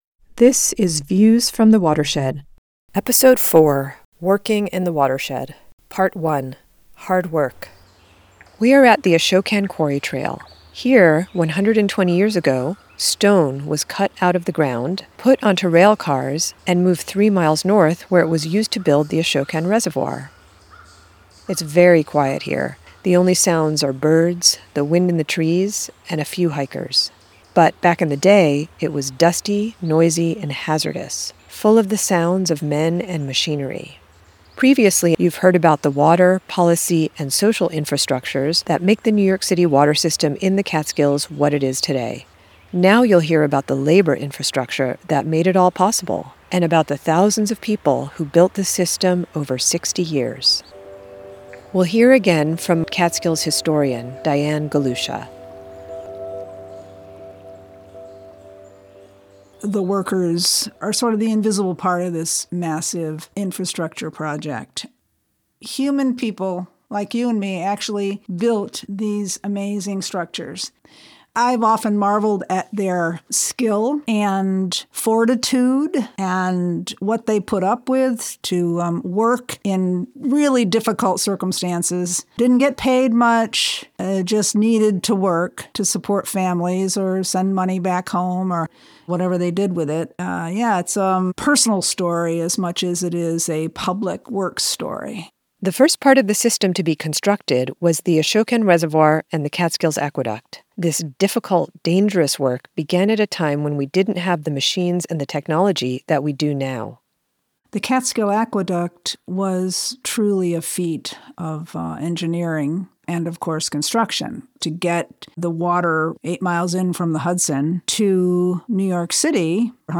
"Views from the Watershed" is about the landscape, history, and politics of New York City’s water supply, and the complicated relationship between the City and the Catskills communities that steward 90% of its water. It tells the stories of the watershed through firsthand, intimate perspectives from local people (including a historian, a dairy farmer, a former DEP commissioner, a grave restorer, and a forester) on what it means to be a part of the water system.